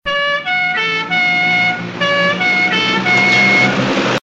OLD SIREN AND ACCELERATION.mp3
Original creative-commons licensed sounds for DJ's and music producers, recorded with high quality studio microphones.
old_siren_and_acceleration_dg1.ogg